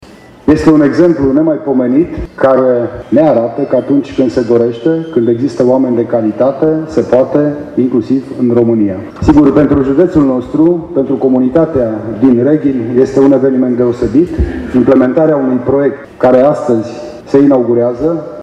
Centrul a fost inaugurat astăzi, în prezența câtorva sute de persoane, angajați, parteneri de afaceri și reprezentanți ai autorităților locale.
Prefectul județului Mureș, Lucian Goga, a subliniat importanța acestei investiții pentru economia județului: